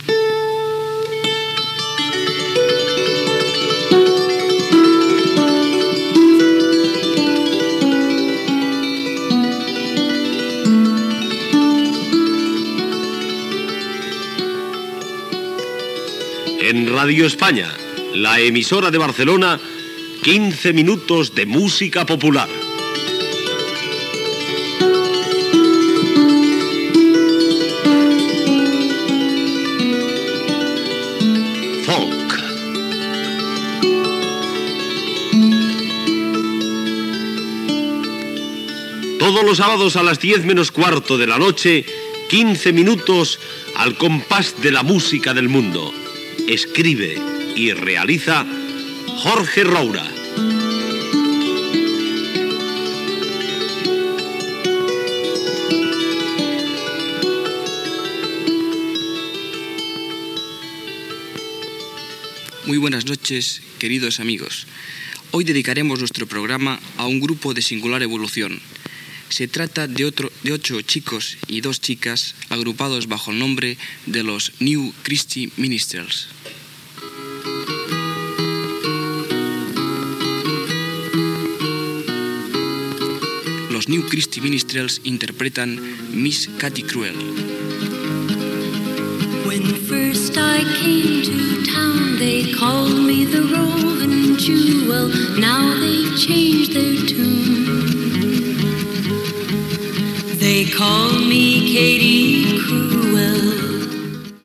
Careta del programa, pesentació d'un tema musical
Musical